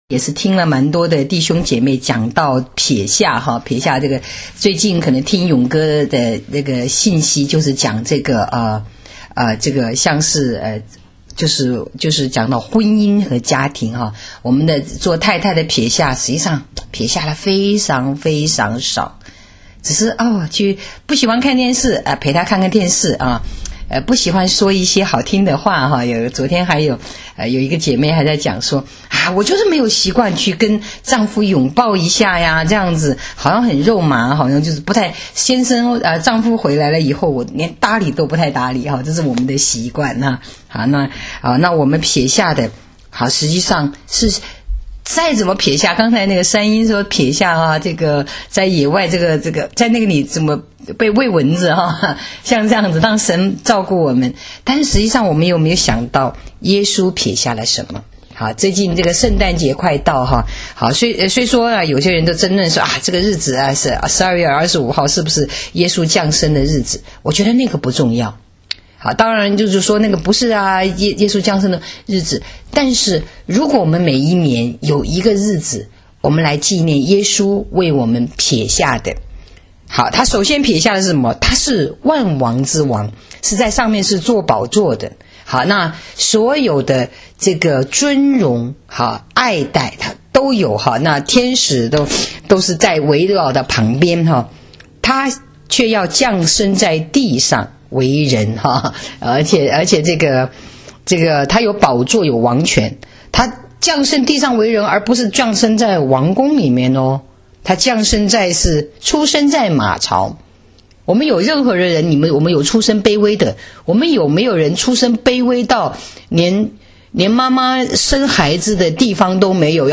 【周六分享】撇下与祝福（12-23-17）